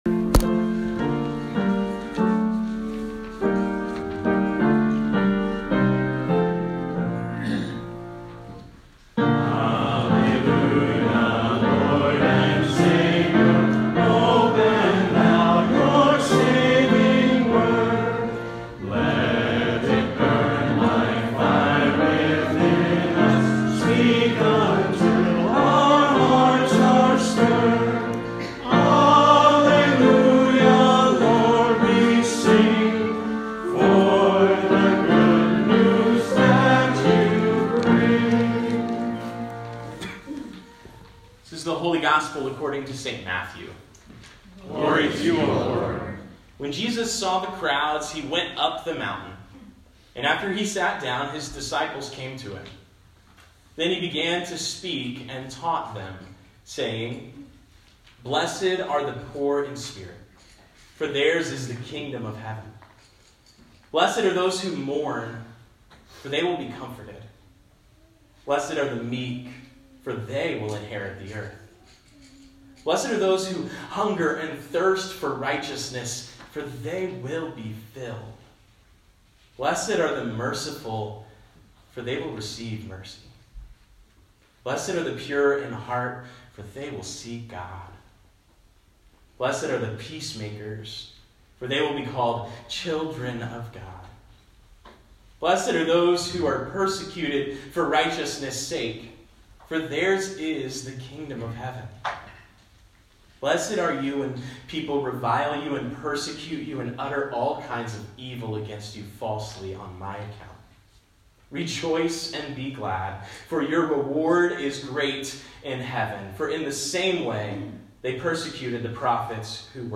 Sermon-Feb-2-2020A-beatitudes.m4a